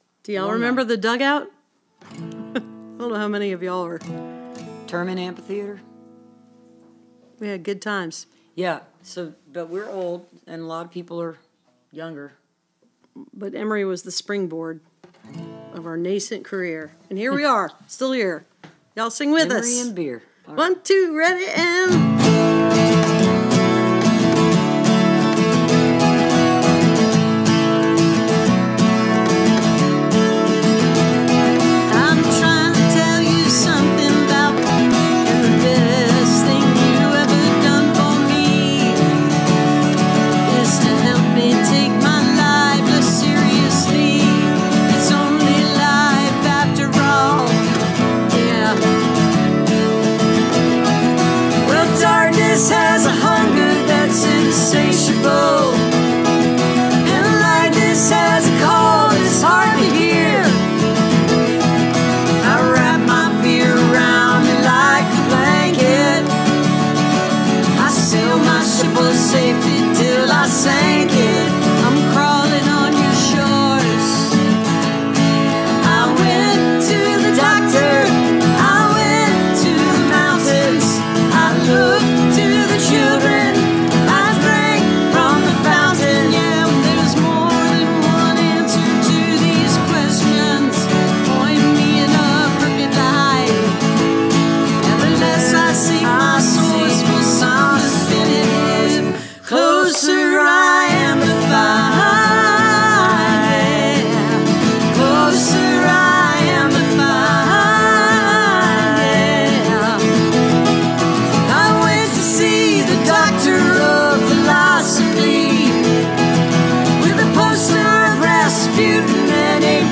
(captured from the youtube livestream)